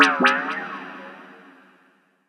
DDWV POP PERC 5.wav